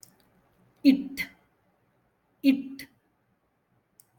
it